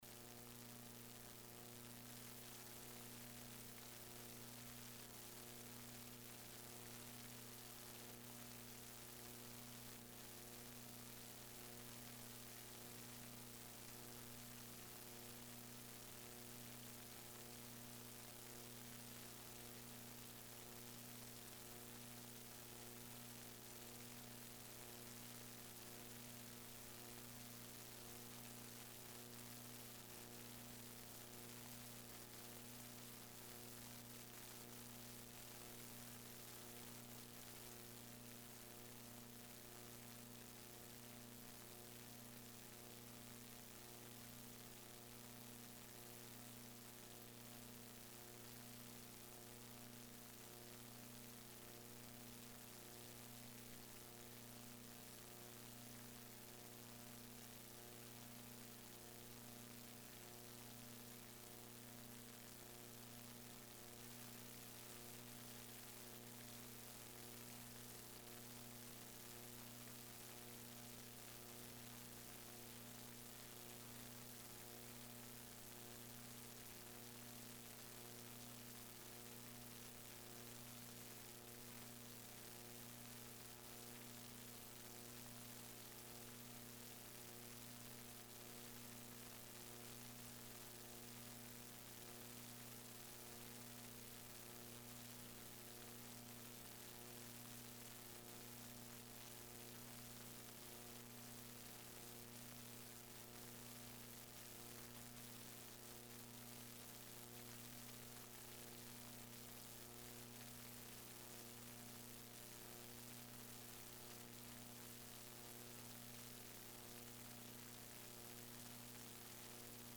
1º. Sessão Extraordinária